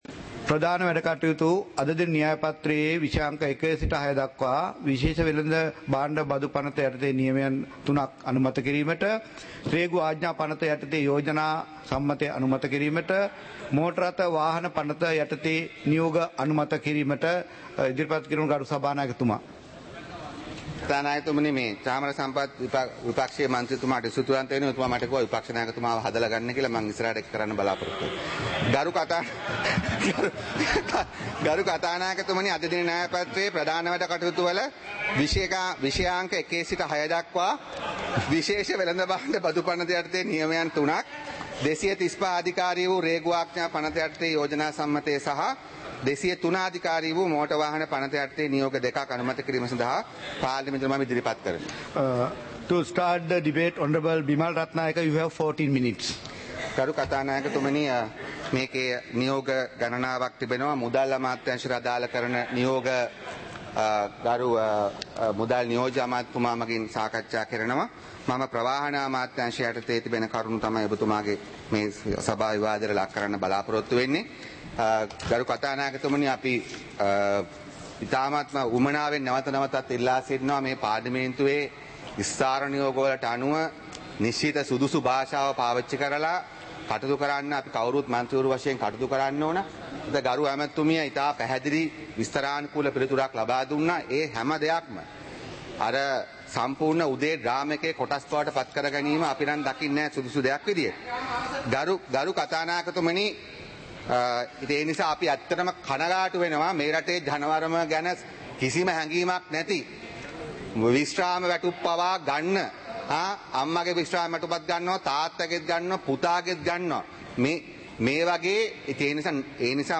சபை நடவடிக்கைமுறை (2026-02-18)
நேரலை - பதிவுருத்தப்பட்ட